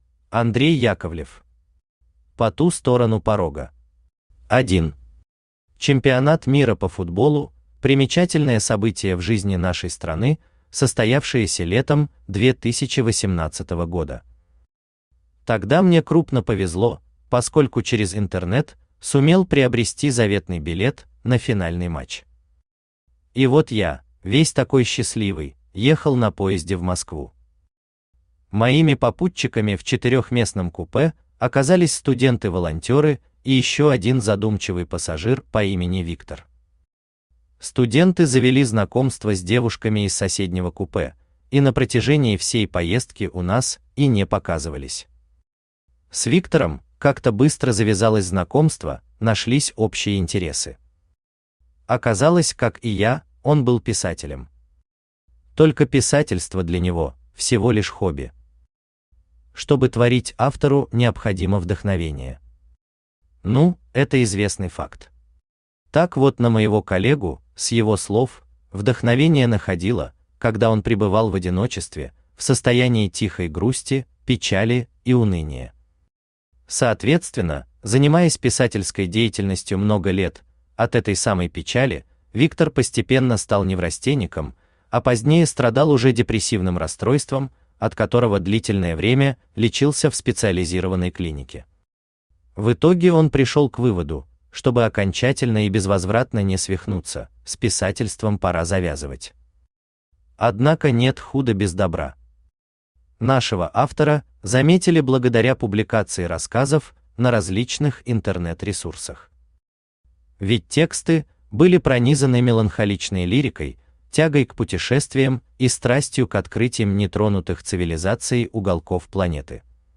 Аудиокнига По ту сторону порога | Библиотека аудиокниг
Aудиокнига По ту сторону порога Автор Андрей Владимирович Яковлев Читает аудиокнигу Авточтец ЛитРес.